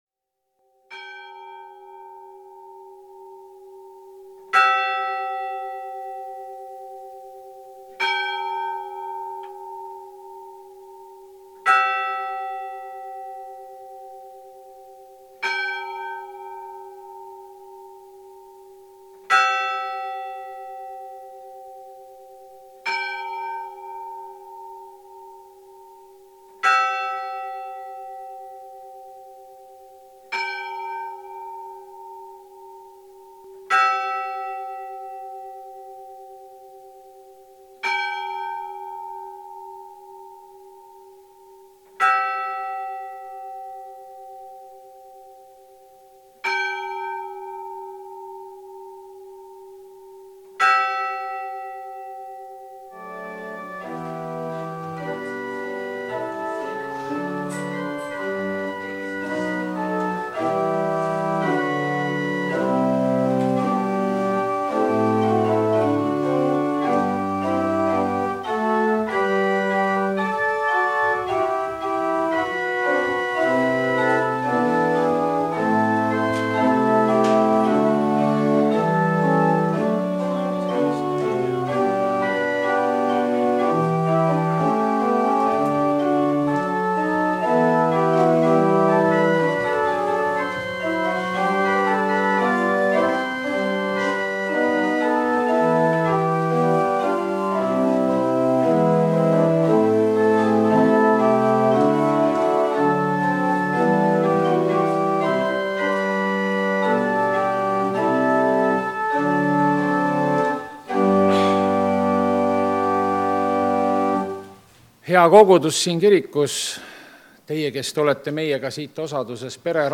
Jumalateenistus 8. märts 2026